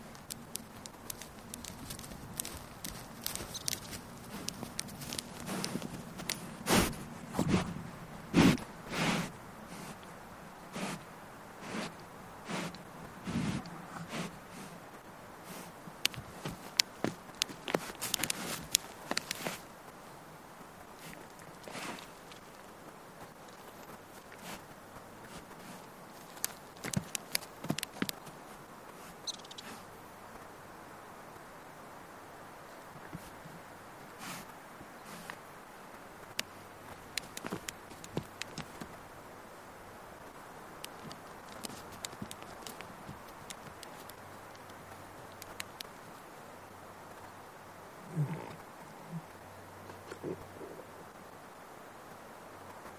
A group of caribou move along the rolling, open tundra hills of the former Pfaff Mine site, Katmai National Park. Each footstep is marked by the characteristic snapping sound of tendons moving over the sesamoid bone of their ankle.
Alaskan Natural Sound Showcase